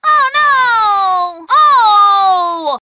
One of Daisy's voice clips in Mario Kart 7